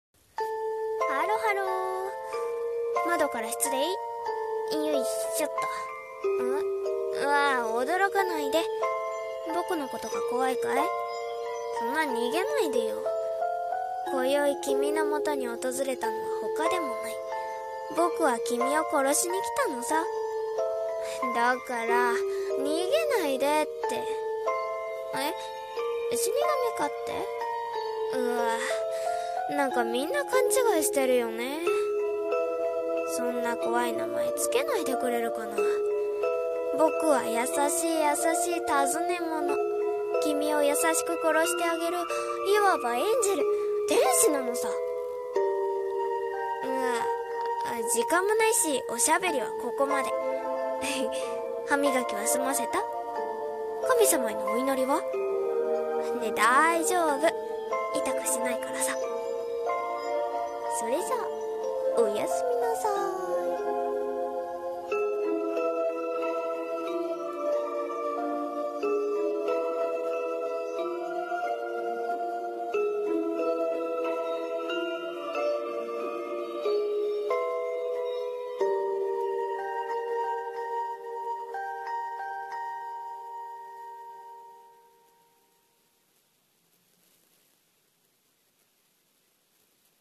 【声劇台本】月夜の訪ね者【ホラー】